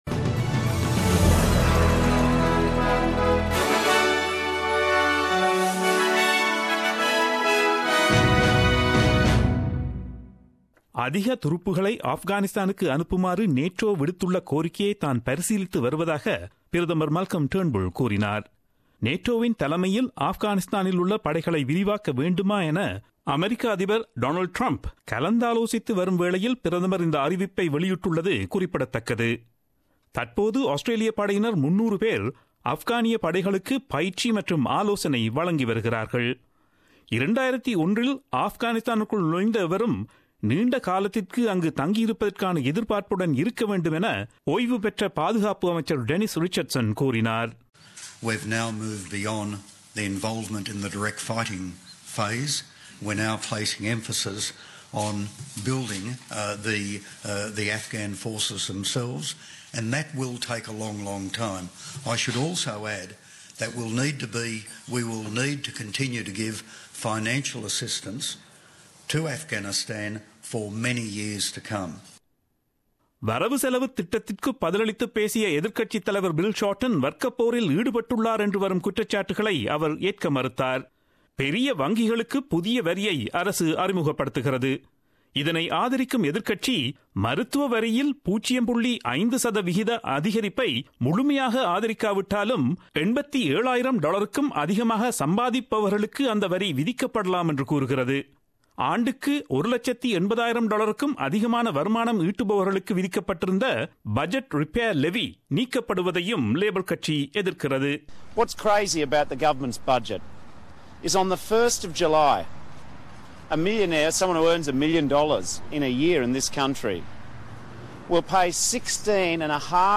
Australian news bulletin aired on Friday 12 May 2017 at 8pm.